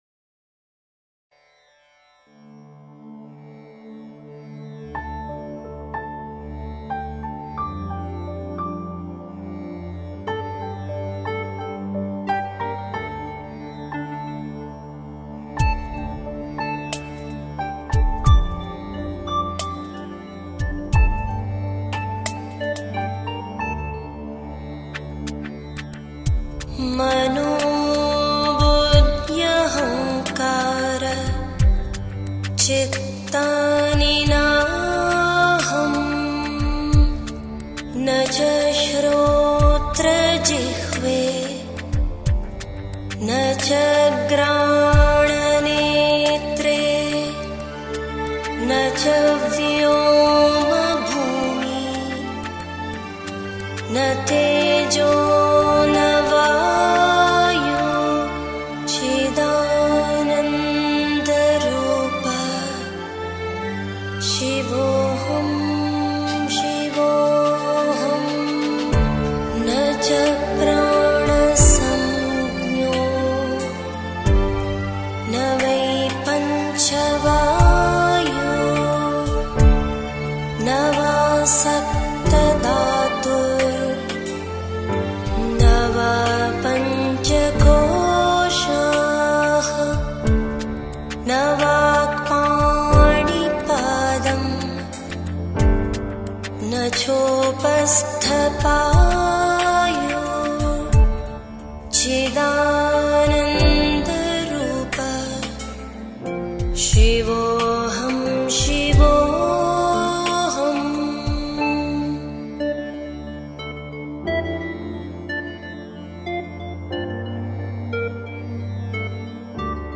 stotra or spiritual verses
What a stunning voice she has, absolutely mesmerizing.